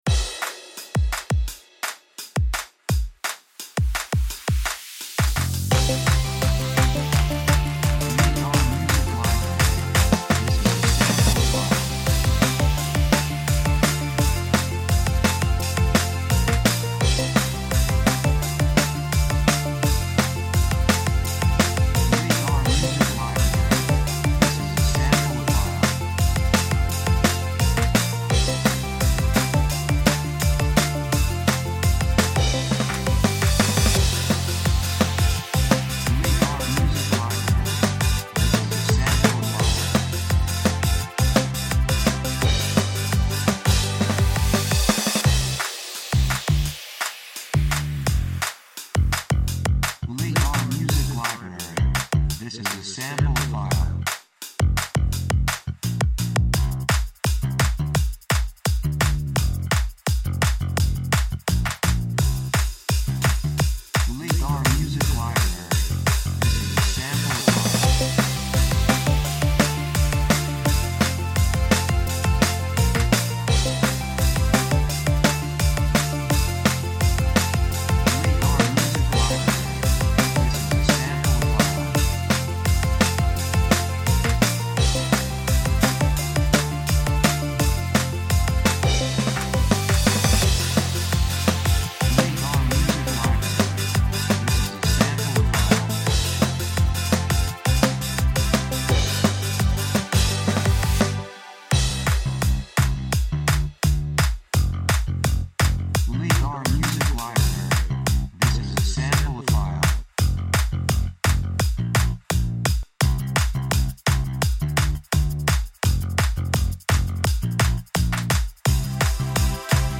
雰囲気エネルギッシュ, 幸せ, 高揚感, 決意, 喜び
曲調ポジティブ
楽器エレキギター, 手拍子
サブジャンルポップロック, インディーロック
テンポとても速い